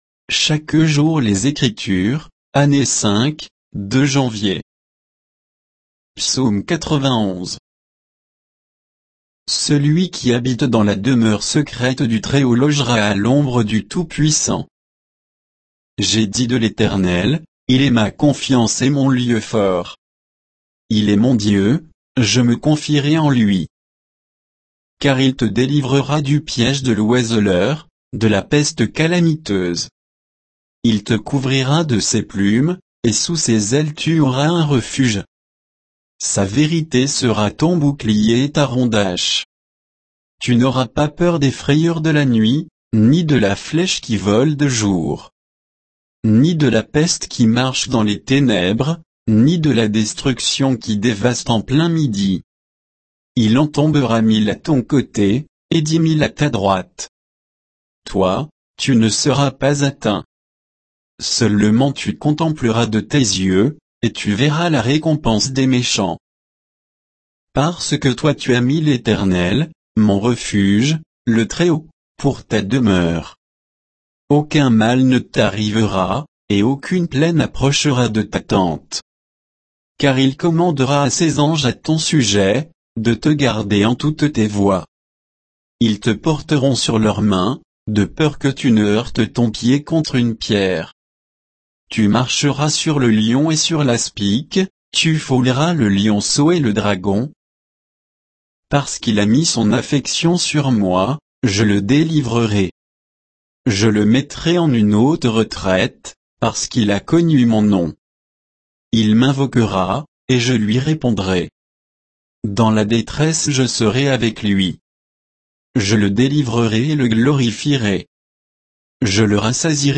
Méditation quoditienne de Chaque jour les Écritures sur Psaume 91